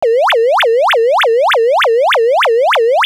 (Fig. 5c): A case where excessive images are present in the signal and have not been appropriately filtered out, resulting in the imaging artifacts. A discrete-time source where linear interpolation was used and the receiver sampling frequency was 44.1 kHz.